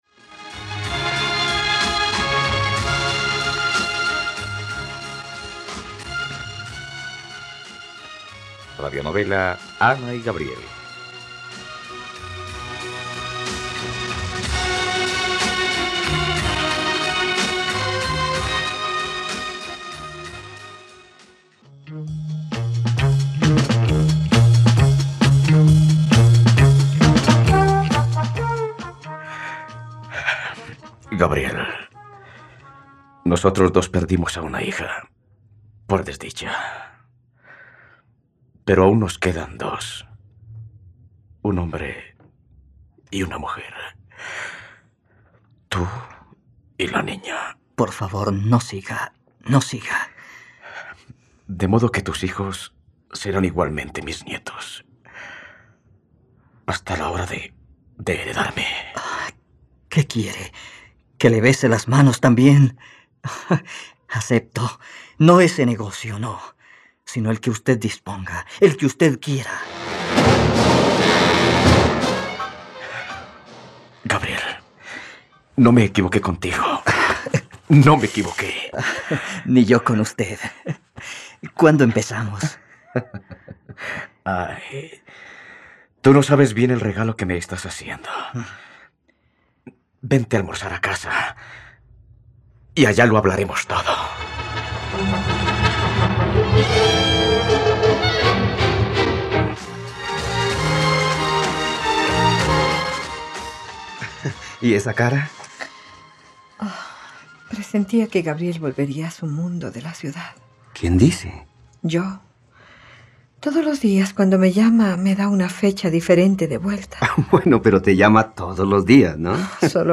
Ana y Gabriel - Radionovela, capítulo 128 | RTVCPlay